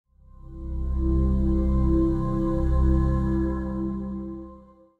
CB_Breath-In_v01.mp3